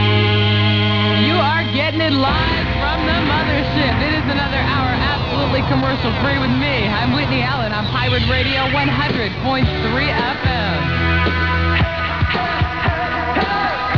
sweeper,